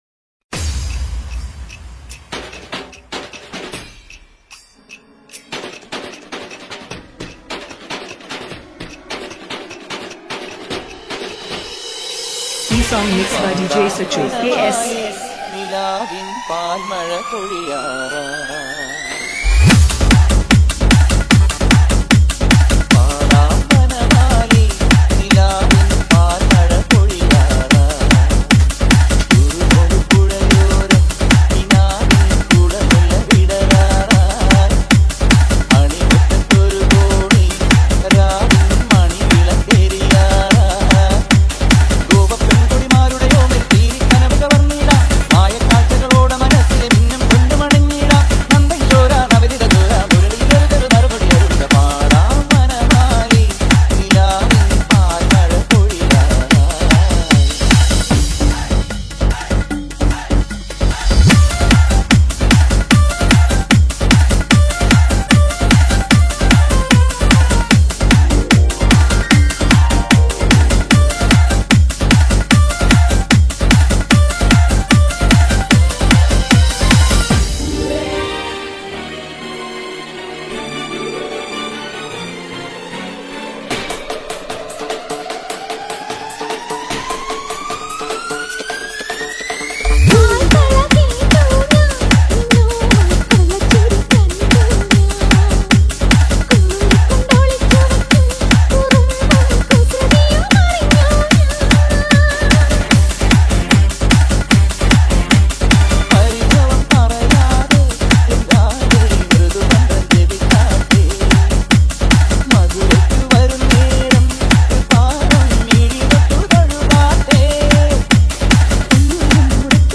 ALL TAMIL LOVE DJ REMIX